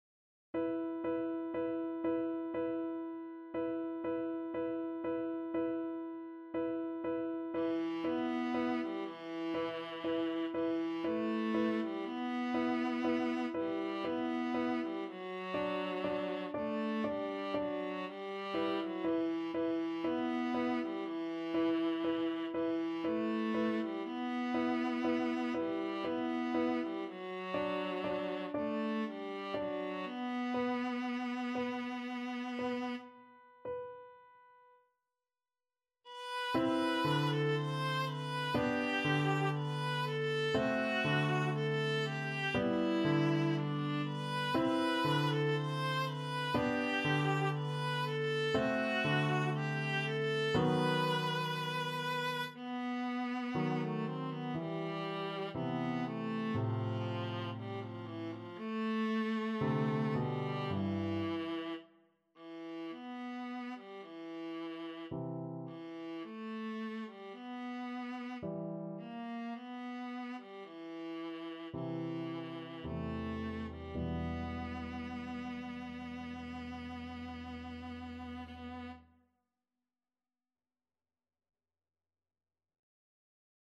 3/4 (View more 3/4 Music)
Andante sostenuto =60
Classical (View more Classical Viola Music)